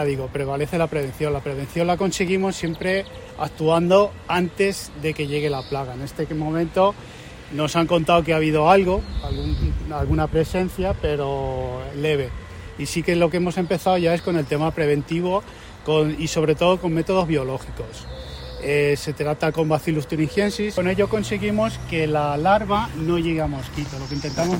tecnico.mp3